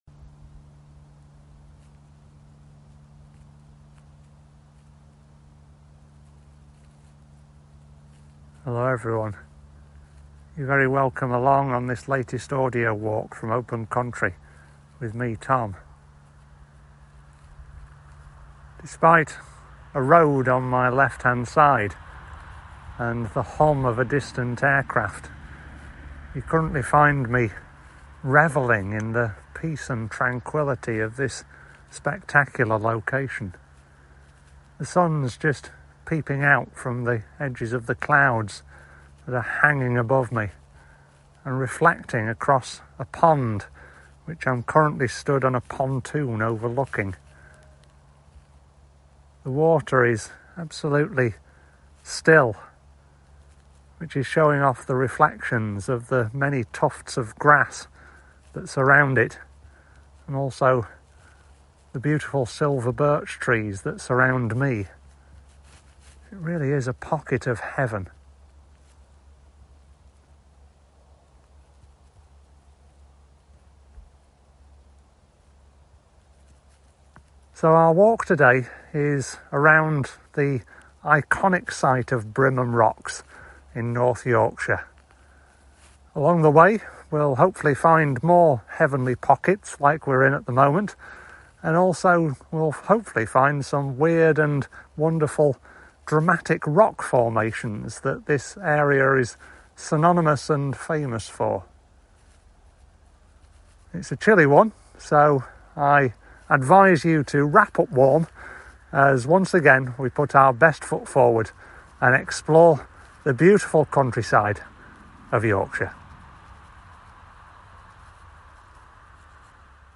Brimham Rocks Audio Walk
Brimham-Rocks-Audio-Walk.mp3